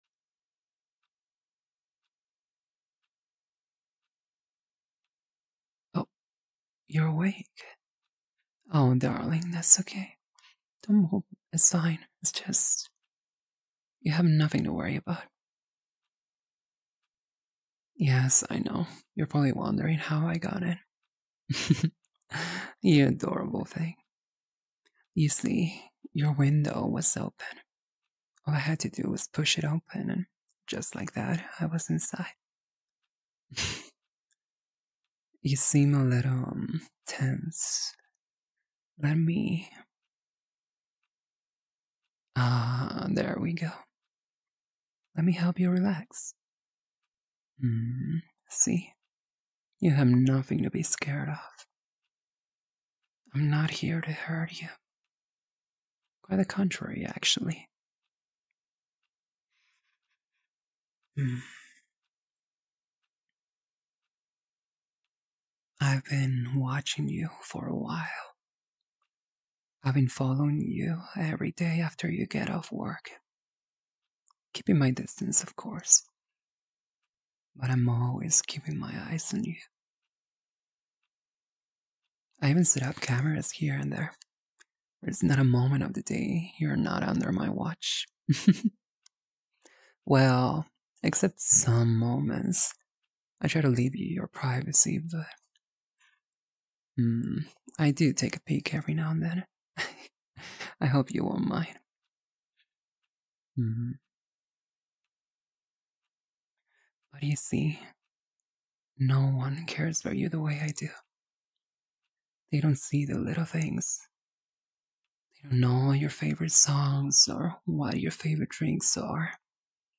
♡ Yandere wolf mommy sneaks into your room and claims you ♡ (asmr) [wrOyuB1e9C0].flac